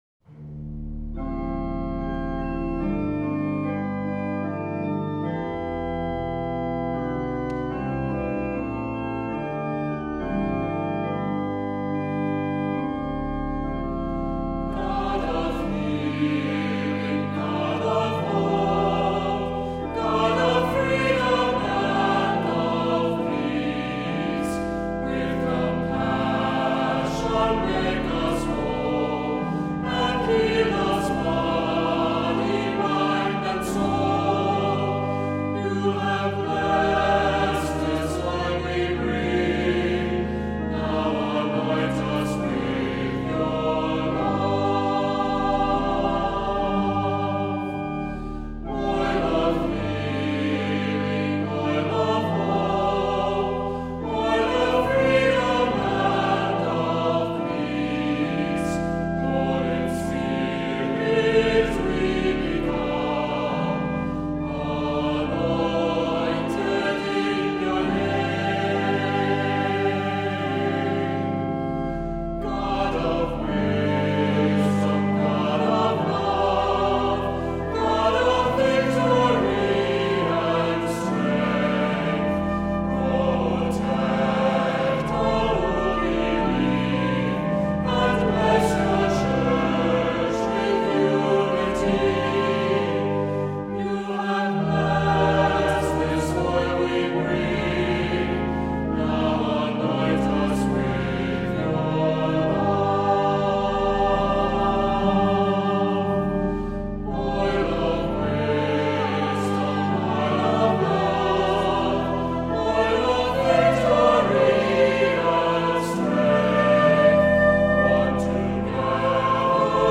Voicing: Unison with descant; Assembly